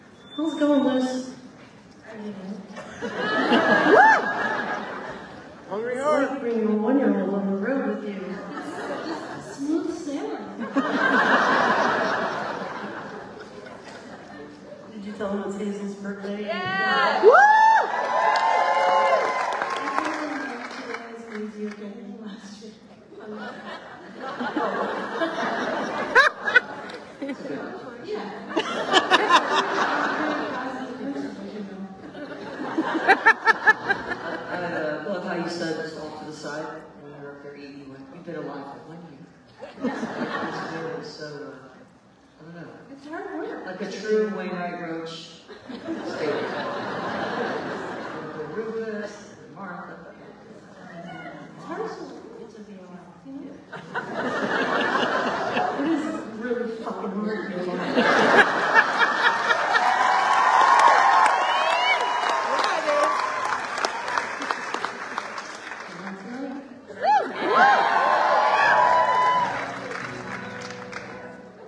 05. talking with the crowd (1:11)